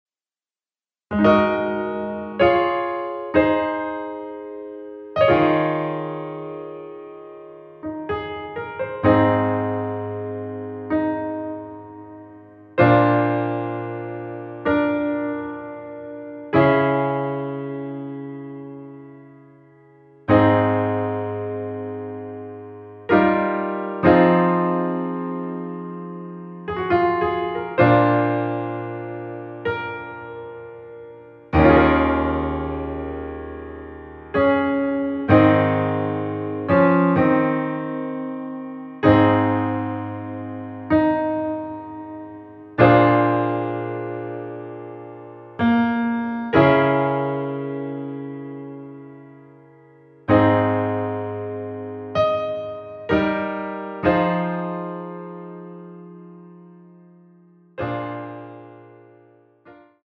피아노만으로 편곡된 MR 입니다.(미리듣기 참조)
Ab
거품도 없고 음질도 좋습니다 ㅎㅎ
앞부분30초, 뒷부분30초씩 편집해서 올려 드리고 있습니다.